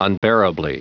Prononciation du mot unbearably en anglais (fichier audio)
Prononciation du mot : unbearably